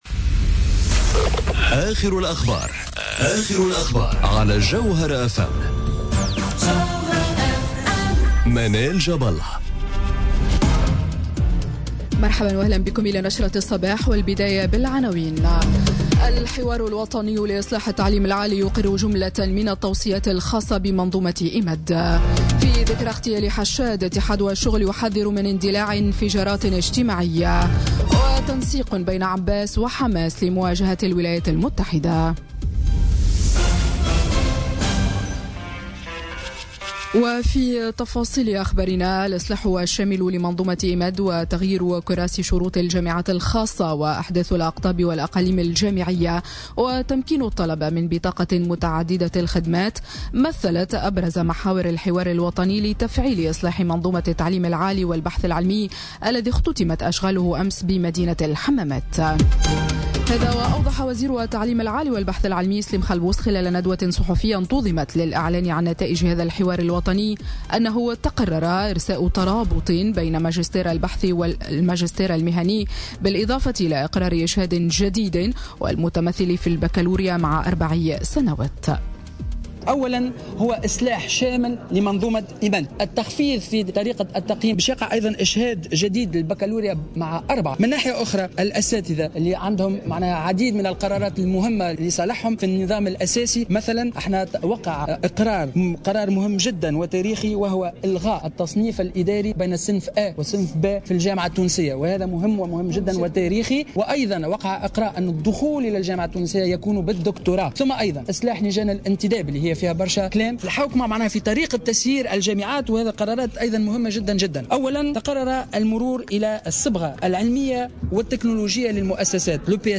نشرة أخبار السابعة صباحا ليوم الإثنين 4 ديسمبر 2017